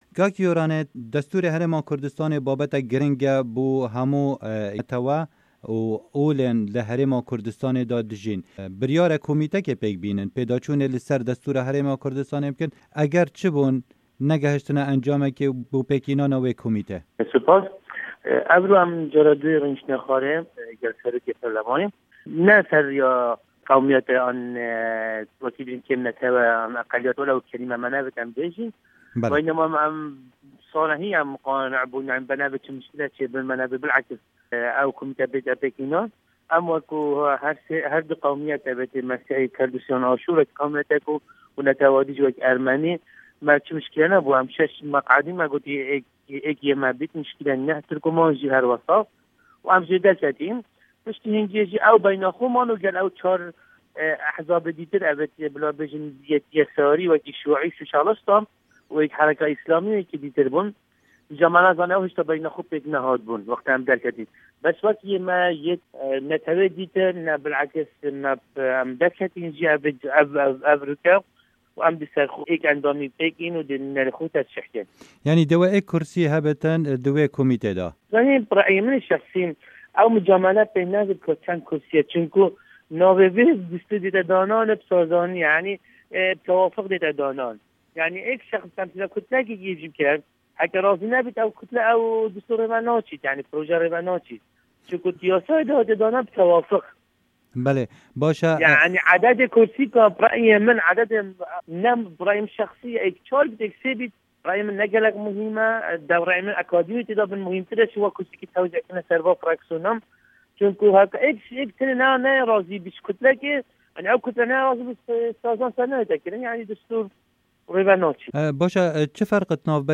Hevpeyvin digel Yoranît Nîsan